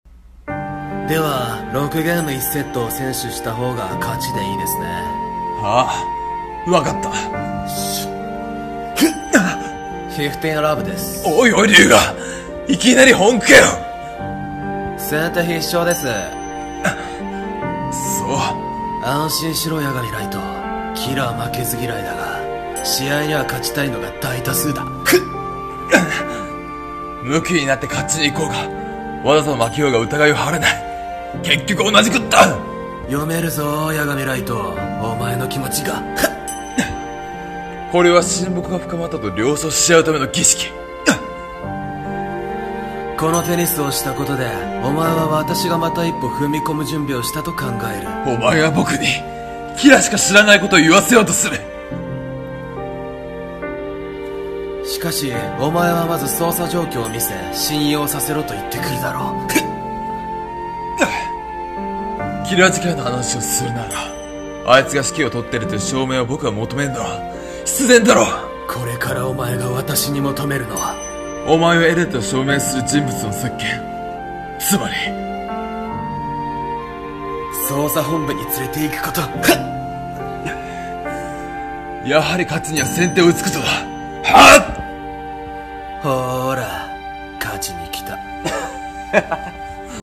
DEATH NOTE 声劇